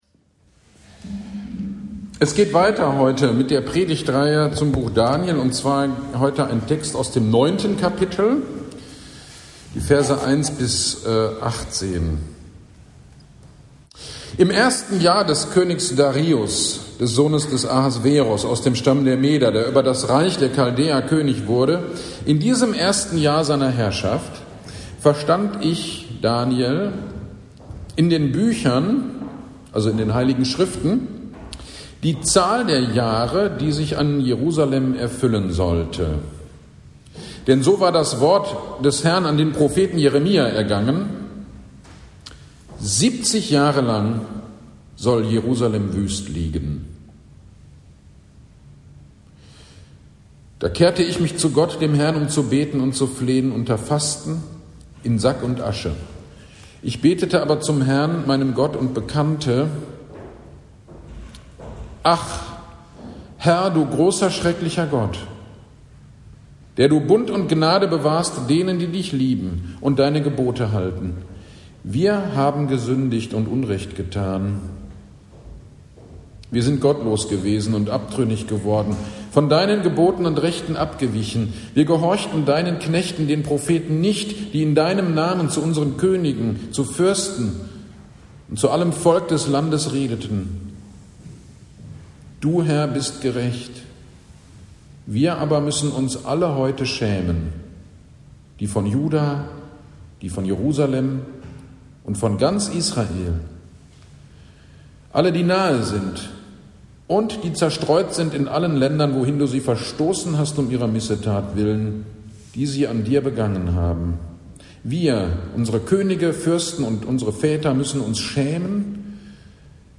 GD am 03.11.24 Predigt zu Daniel 9, 1-18